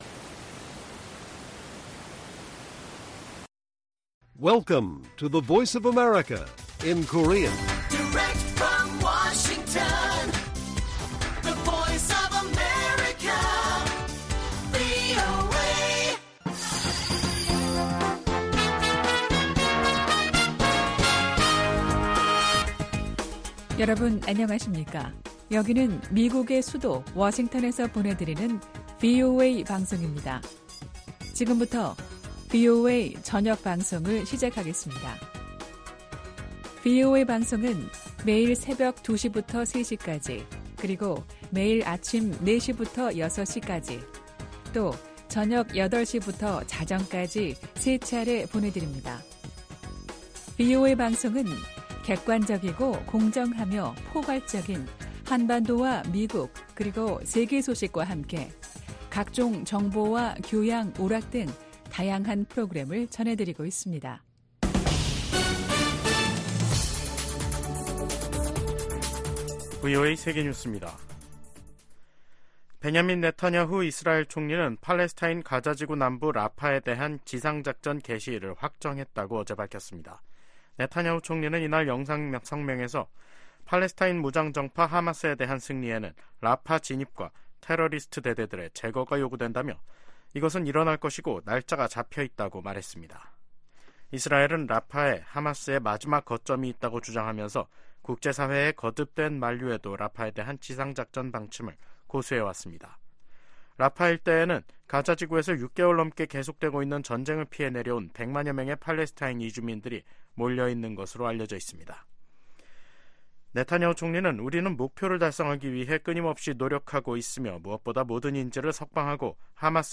VOA 한국어 간판 뉴스 프로그램 '뉴스 투데이', 2024년 4월 9일 1부 방송입니다. 10일 백악관에서 열리는 미일 정상회담이 두 나라 관계의 새 시대를 여는 첫 장이 될 것이라고 주일 미국대사가 말했습니다. 미국 하원의 일본계 중진의원은 미일 동맹이 역대 최고 수준이라며 이번 주 미일 정상회담에 대한 큰 기대를 나타냈습니다.